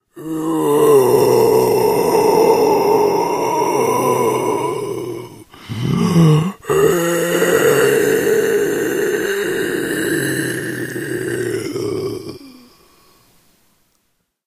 zombie_idle_17.ogg